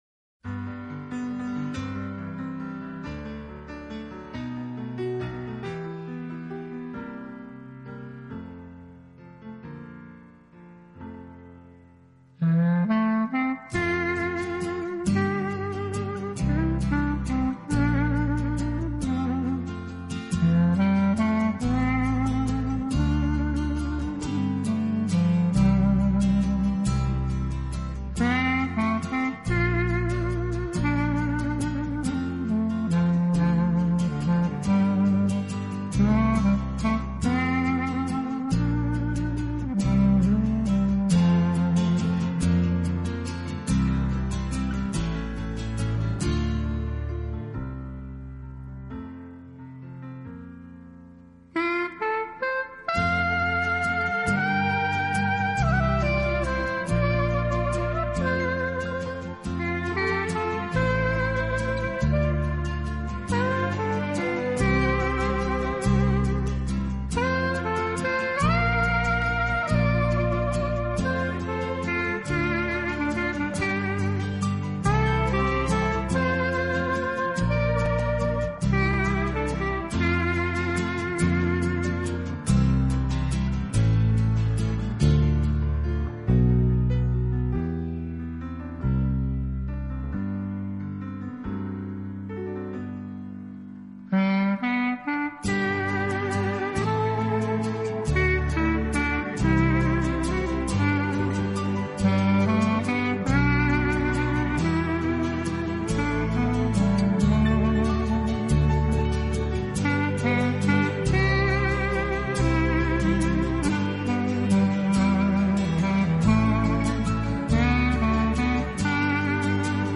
【黑管】
这张精选专辑汇集了这位黑管大师流行经典的曲目，风格轻快，带人回到大乐队时代，